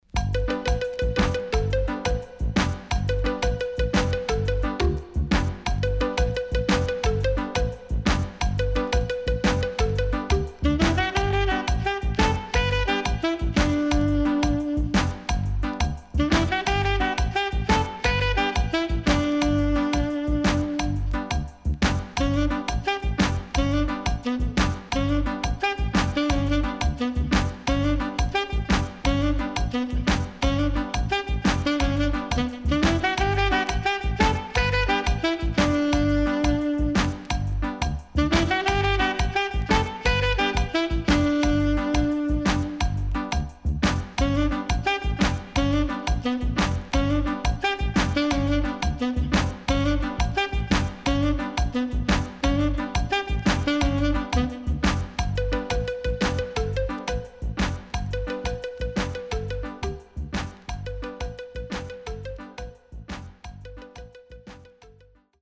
HOME > LP [DANCEHALL]